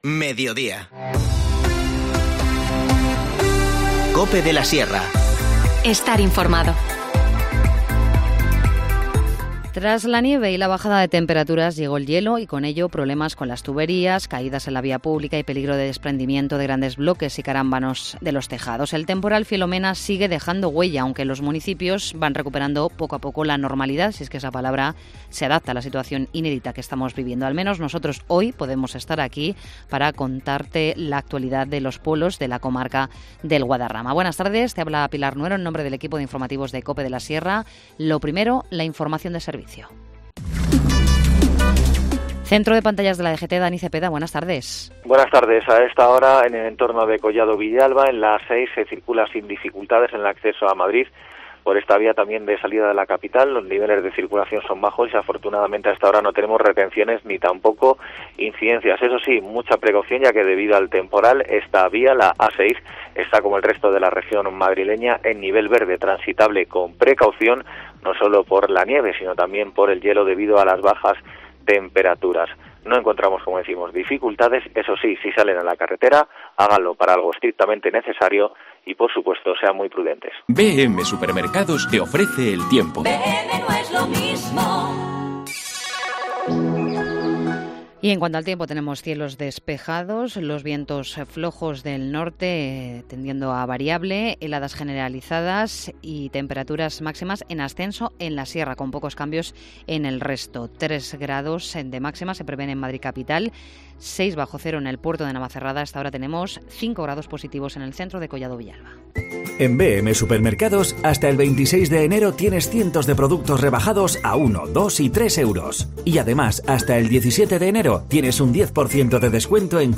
Informativo Mediodía 12 enero
INFORMACIÓN LOCAL